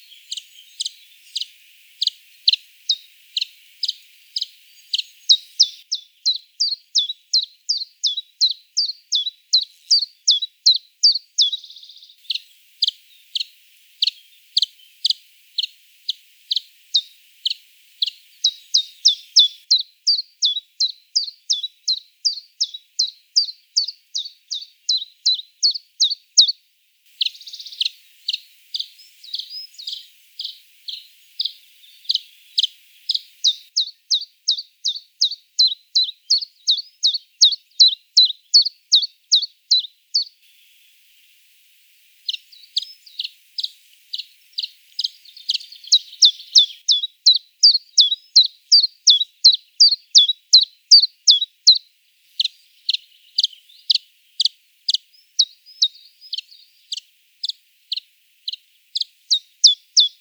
Phylloscopus collybita
- SEX/AGE: adult male and young male (not "crystallized" song) - COMMENT: A juvenile male is learning from an adult singing bird. The song phrases of the juvenile bird show an uncertain rhythm and pitch and wrong syllables mixed with nearly right ones.
- MIC: (P with Tascam DR 100 MKIII)